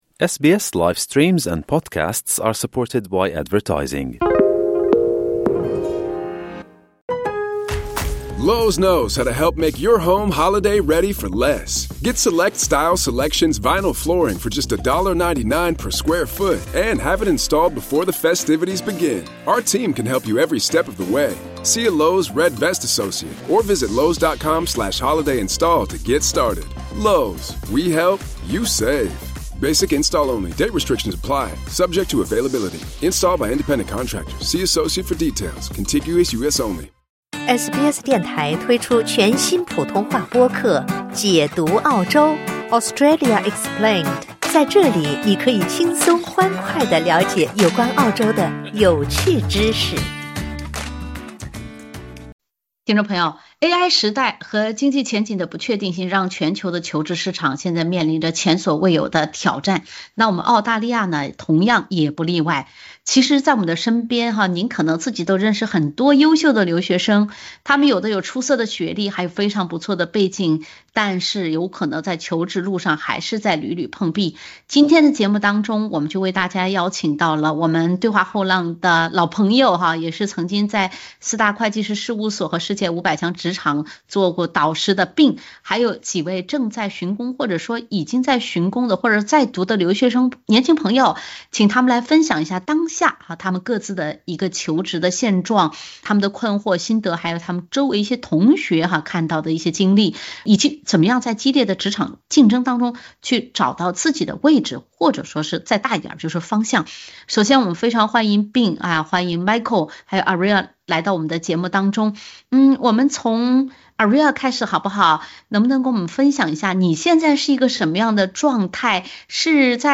留在澳洲找工作，还是回国发展？一位奔波于中澳两地的职业规划师和两位正在求职的留学生亲述自己毕业面临的就业焦虑与选择。